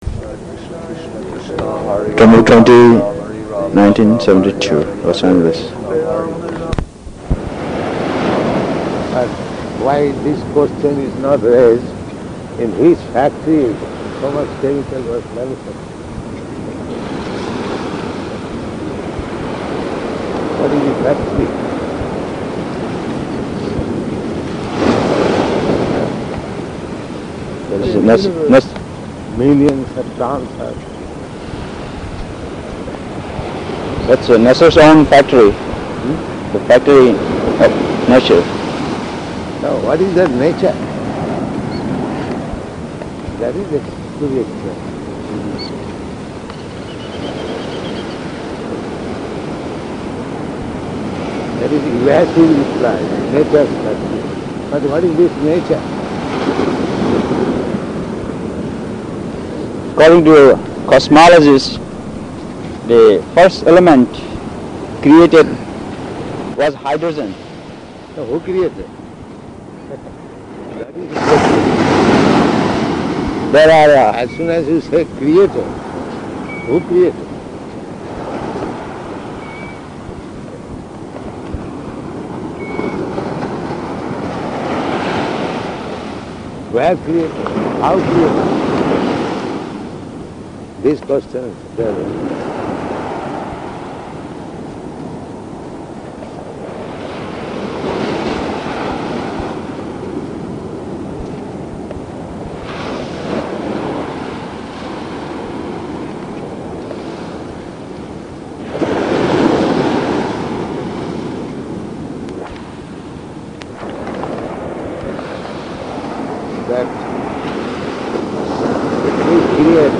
-- Type: Walk Dated: September 20th 1972 Location: Los Angeles Audio file